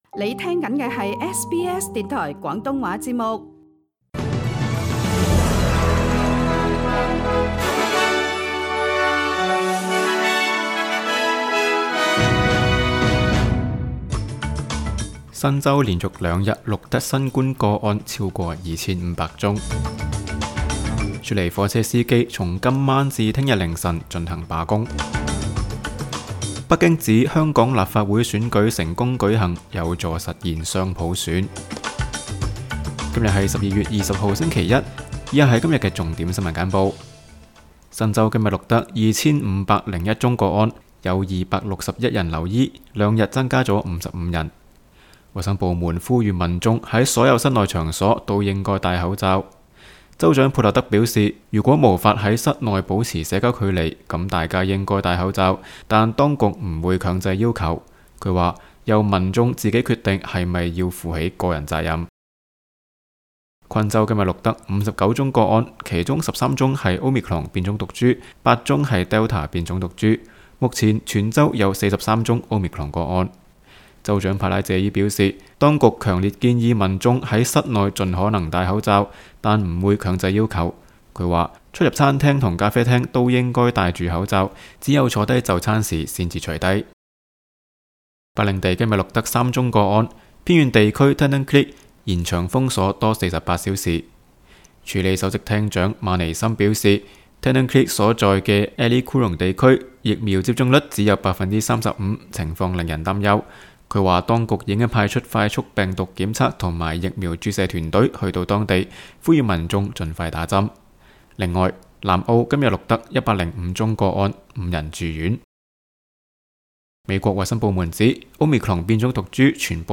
SBS 新闻简报（12月20日）